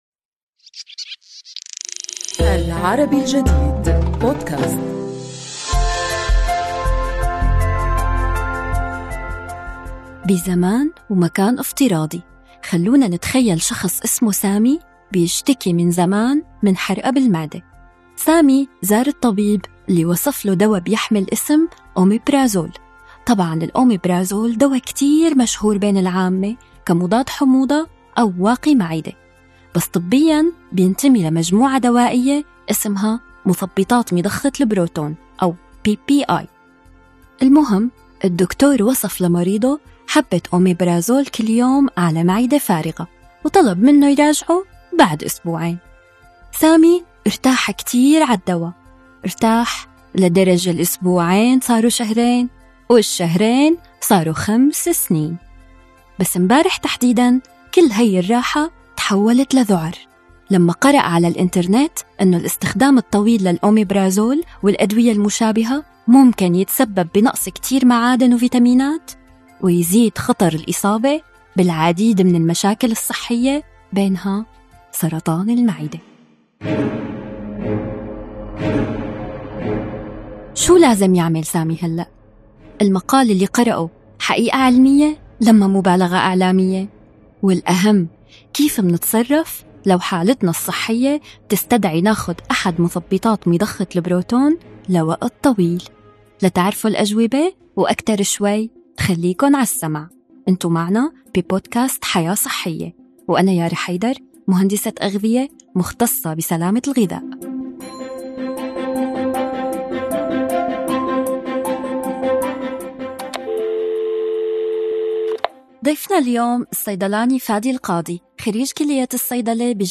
للإجابة عن هذه الأسئلة، نستضيف الصيدلاني